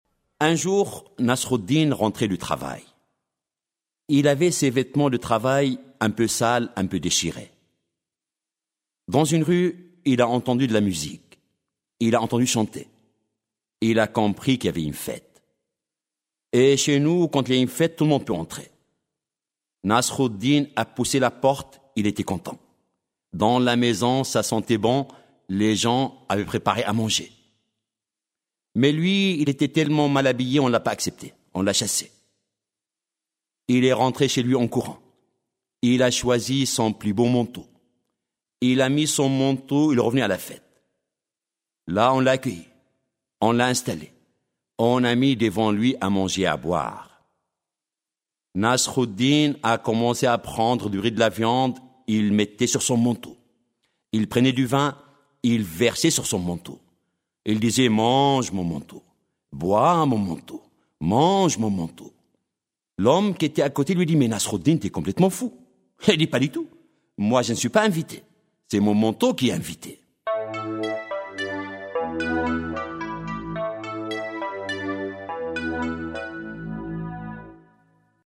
Un livre audio de plus de 40 histoires tout en sagesse, à la lisière de l’absurde et de la raison.